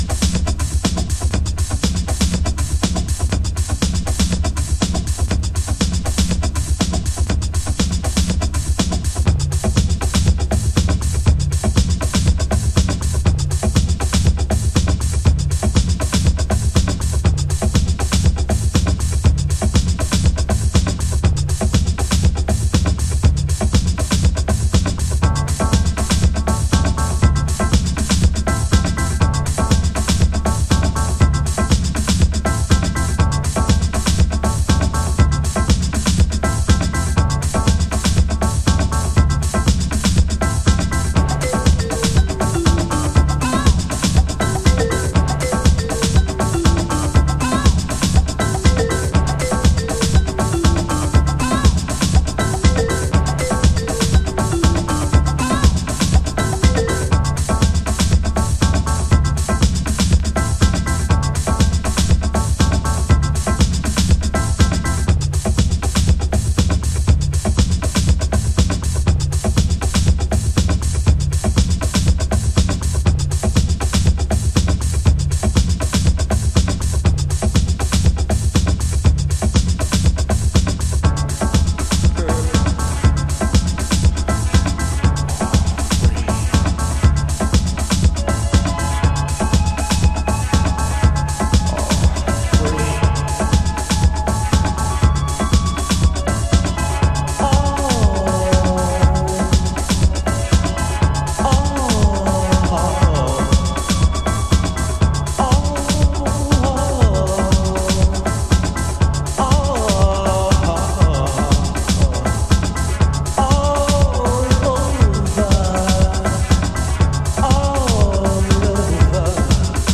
Early House / 90's Techno
NY初期ハウス古典。
オールドスクール & ストリクトリーなハウスグルーヴが楽しめますよ。